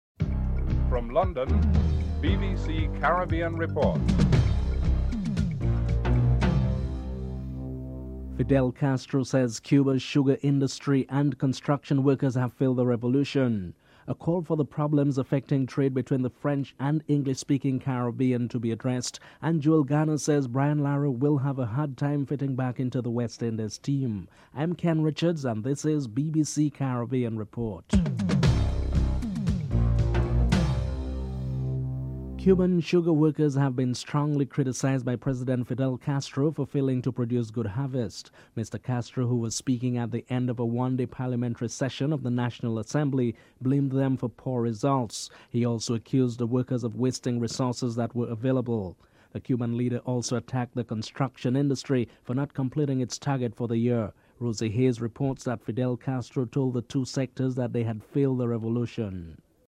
The British Broadcasting Corporation
1. Headlines